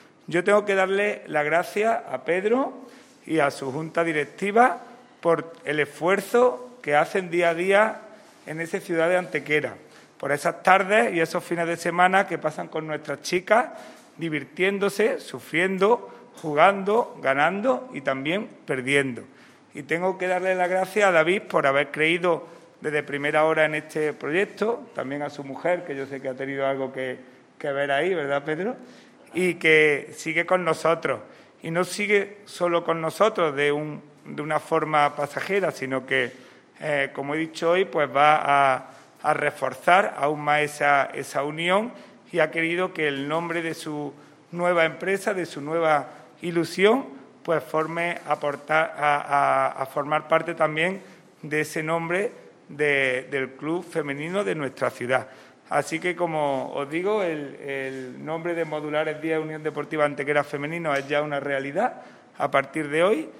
Por otra parte, en el transcurso de la rueda de prensa de presentación de esta nueva esponsorización se ha confirmado que el jueves 5 de agosto se disputará la segunda edición del torneo de fútbol femenino "Peña de los Enamorados", en el que participarán tanto el Modulares Diez U.D. Antequera Femenino como el Real Betis Féminas B de la Segunda División Femenina.
Cortes de voz